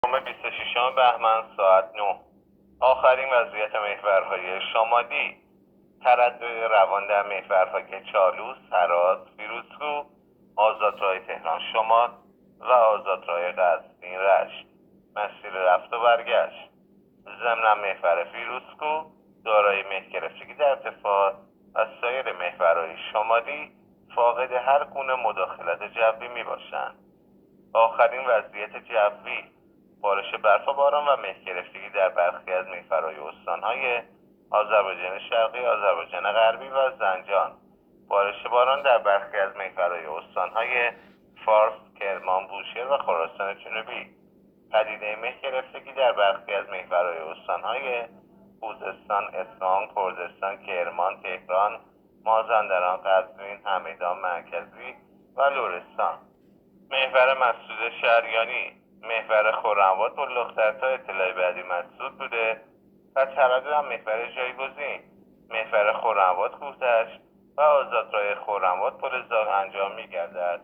گزارش رادیو اینترنتی از آخرین وضعیت ترافیکی جاده‌ها ساعت ۹ بیست و ششم بهمن؛